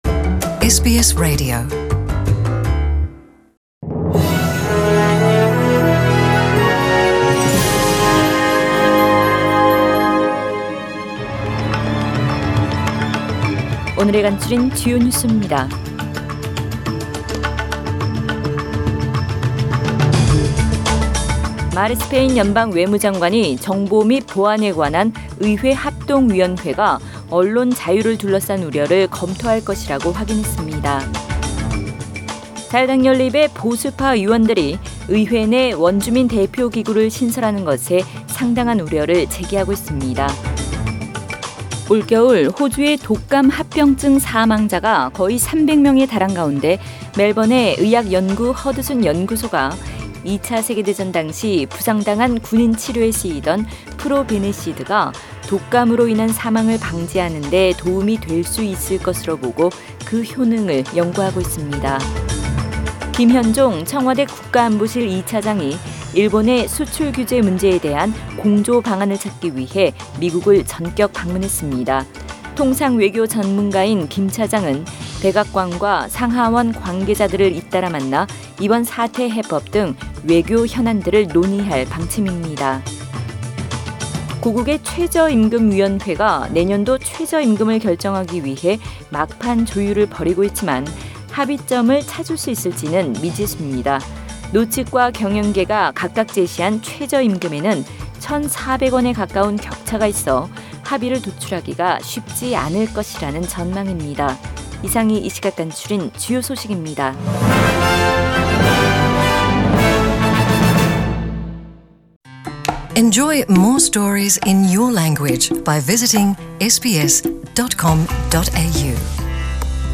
SBS 한국어 뉴스 간추린 주요 소식 – 7월 11일 목요일